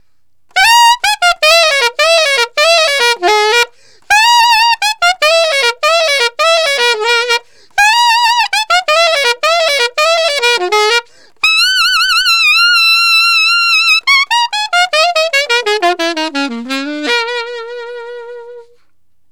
Alto One Shot in Bb 02.wav